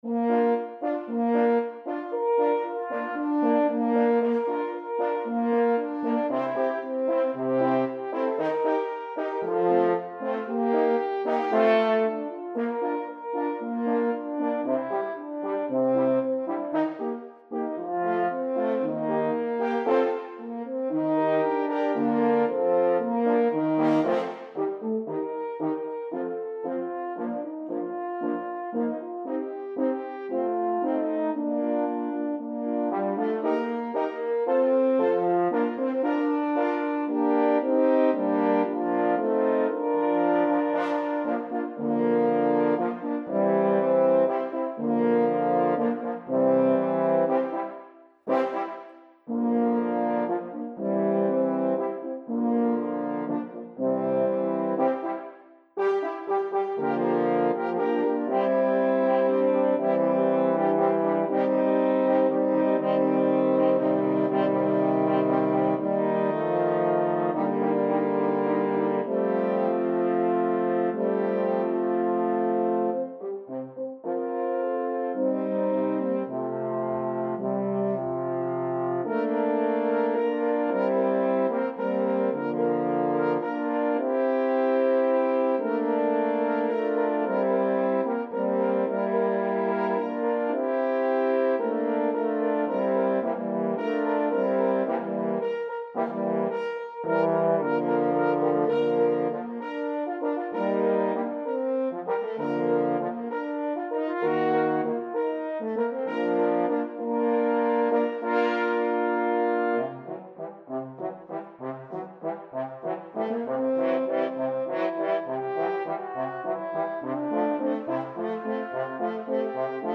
Horns Ensemble
Instruments: F Horn
Horns Ensemble, digital instruments.